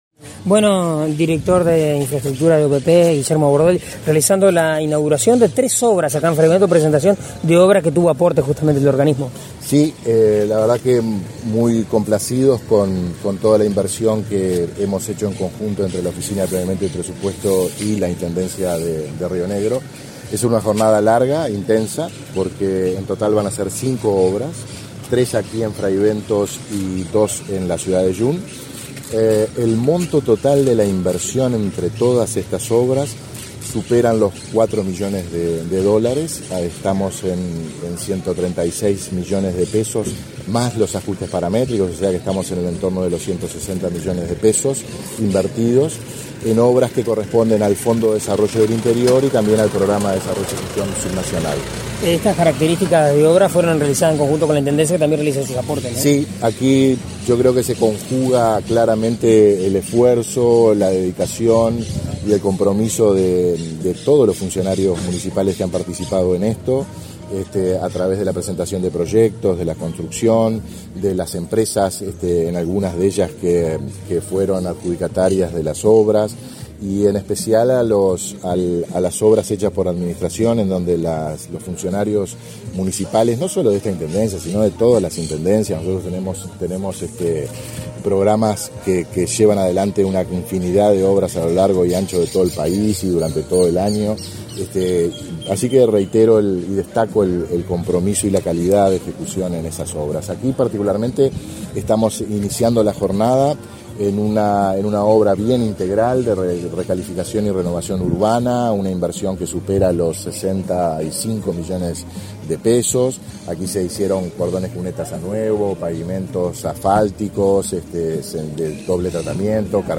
Declaraciones a la prensa del director de Infraestructura de la OPP, Guillermo Bordoli
En la oportunidad, el director de Infraestructura de la OPP, Guillermo Bordoli, realizó declaraciones a la prensa.